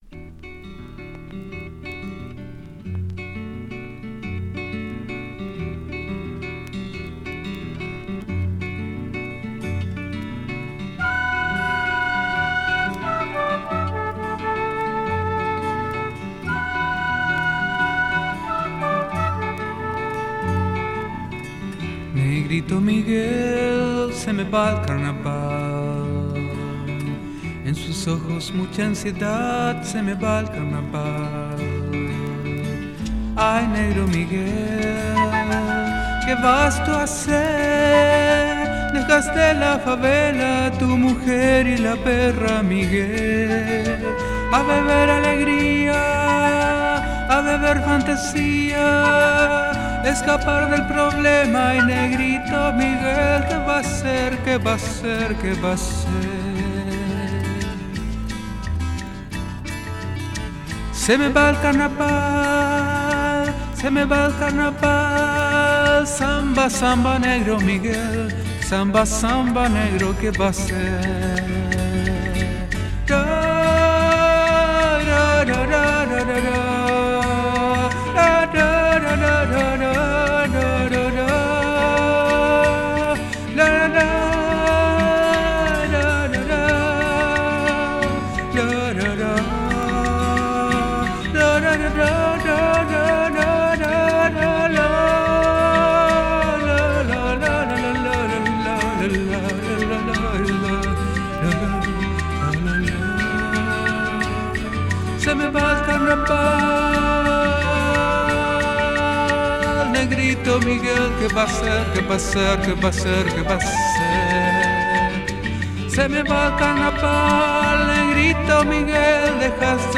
フォルクローレの感覚を色濃く残した極上アシッド・フォーク！